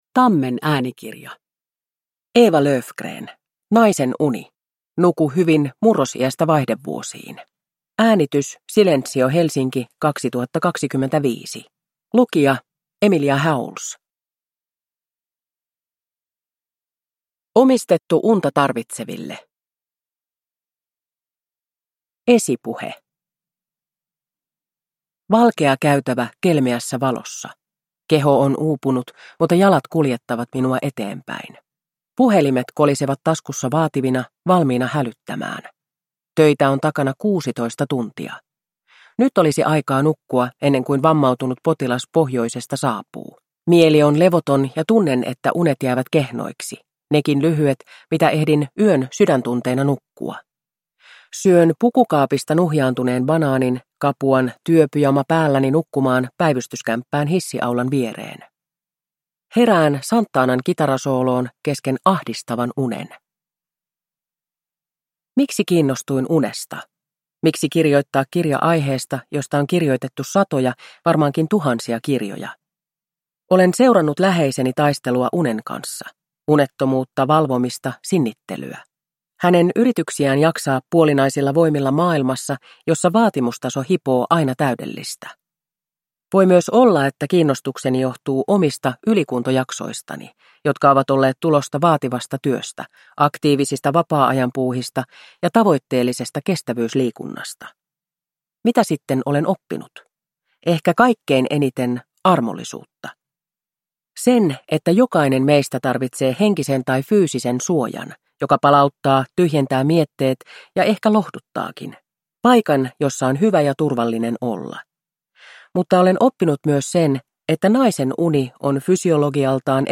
Naisen uni – Ljudbok